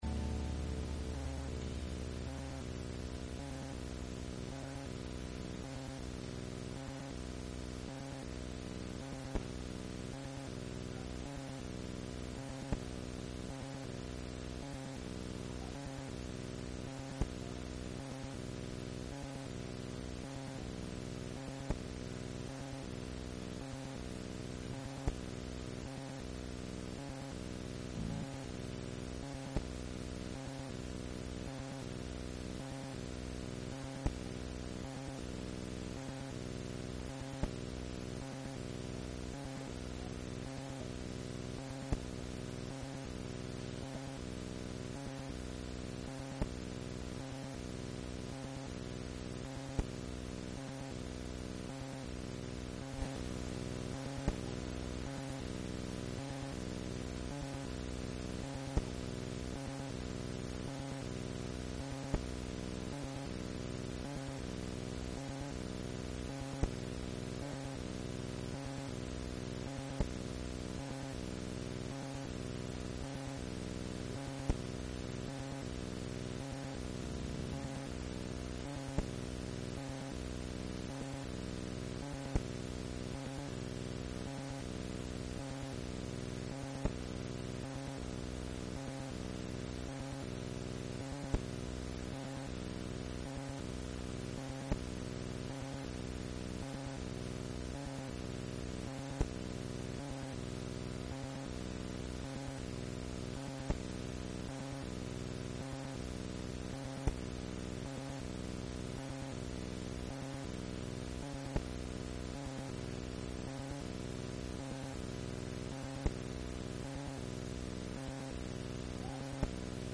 Book of Nehemiah Service Type: Sunday Morning %todo_render% « Nehemiah 6